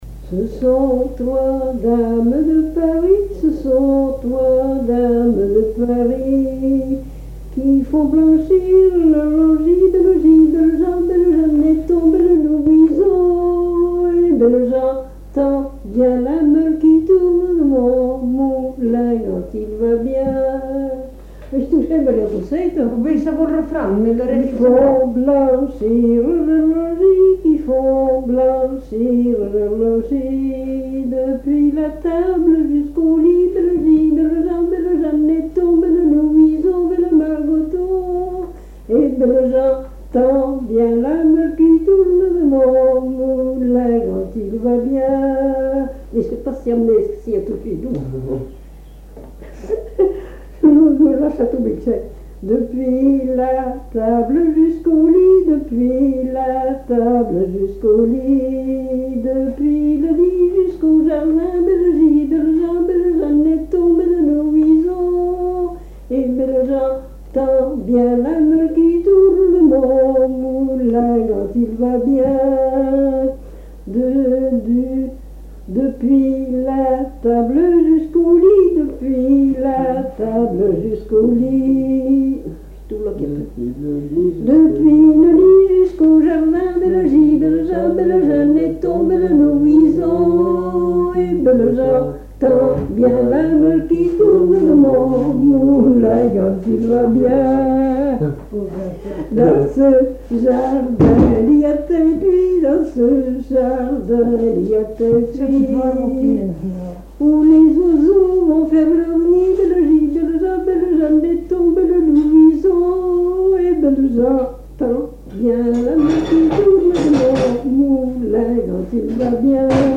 danse : ronde : grand'danse
Pièce musicale inédite